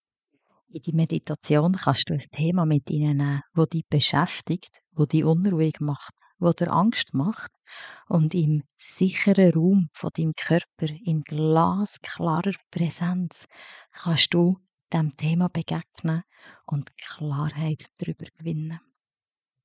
GEFÜHRTE EMBODIMENT PRAXIS